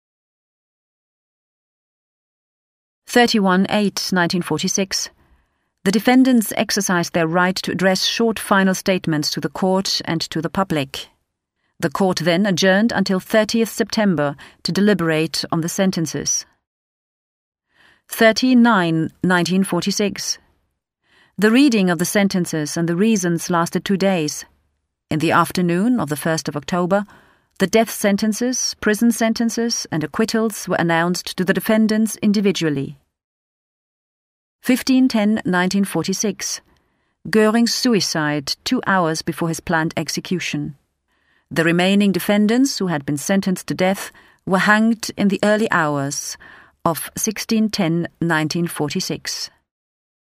Audioguide files